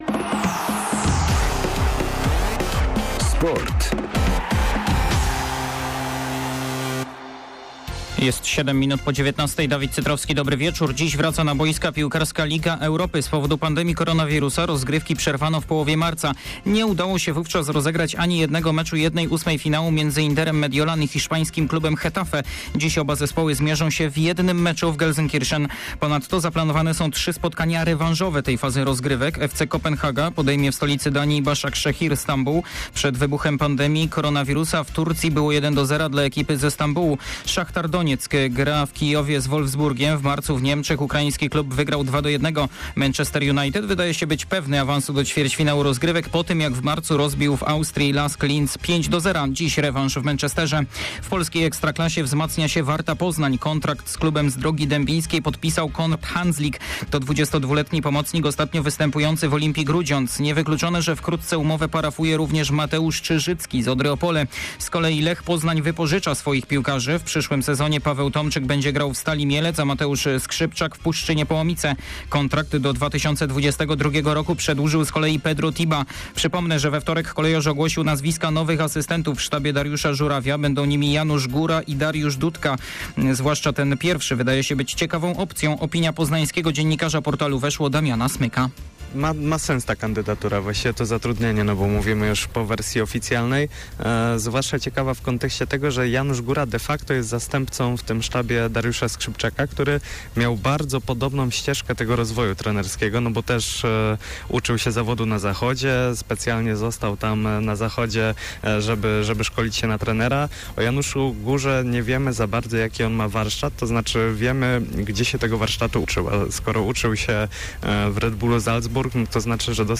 05.08. SERWIS SPORTOWY GODZ. 19:05